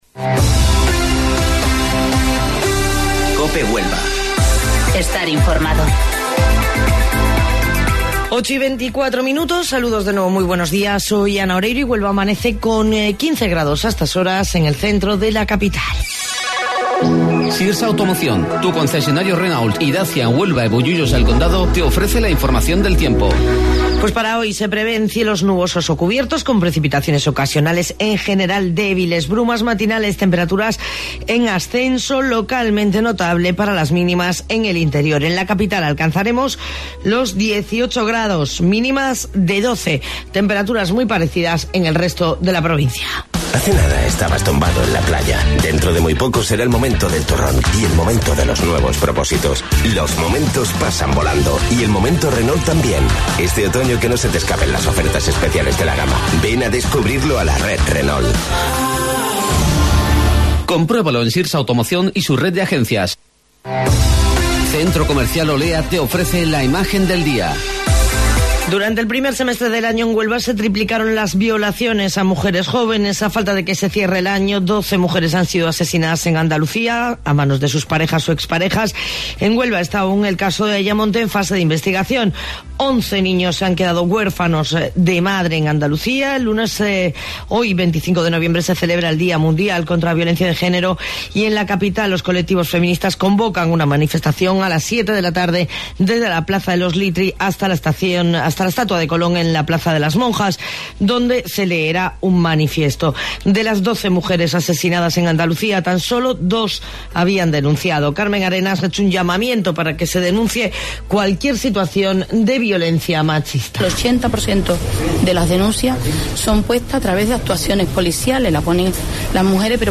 AUDIO: Informativo Local 08:25 del 25 de Noviembre